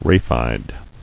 [ra·phide]